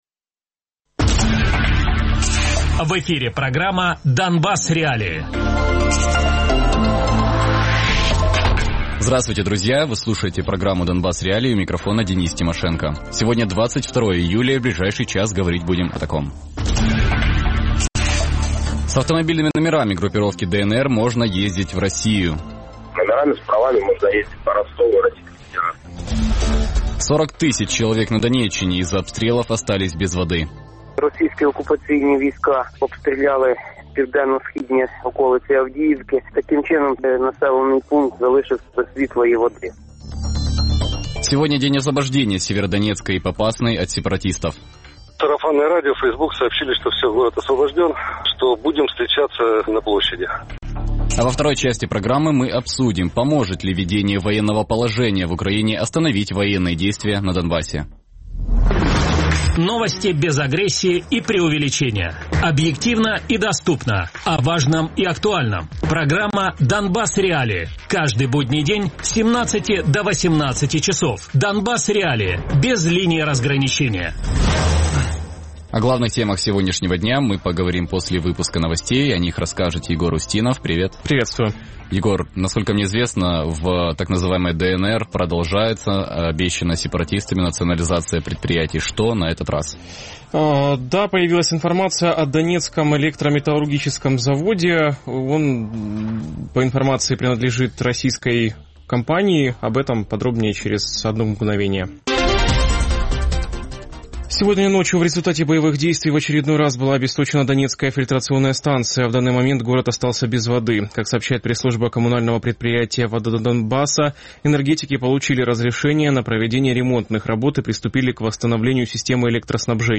Гости студии: военный эксперт
Радіопрограма «Донбас.Реалії» - у будні з 17:00 до 18:00.